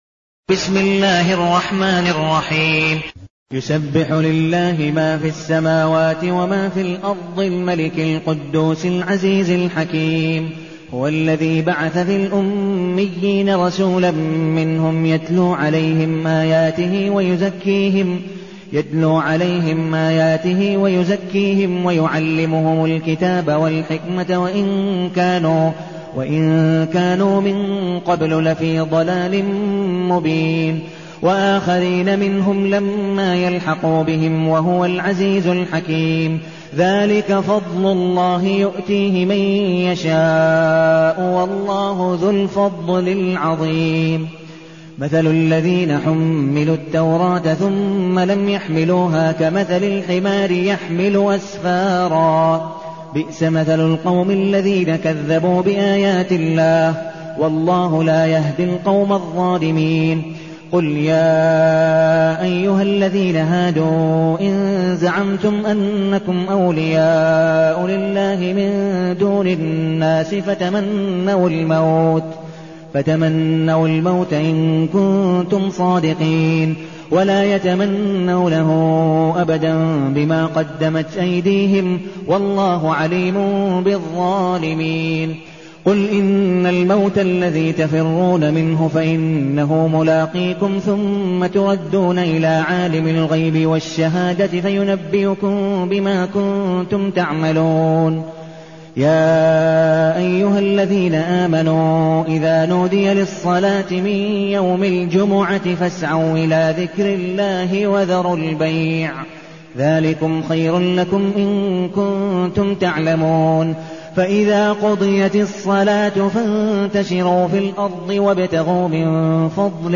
المكان: المسجد النبوي الشيخ: عبدالودود بن مقبول حنيف عبدالودود بن مقبول حنيف الجمعة